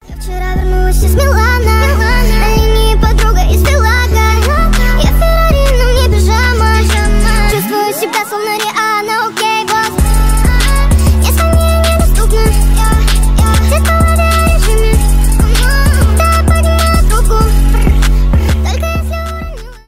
быстрые , ремиксы
поп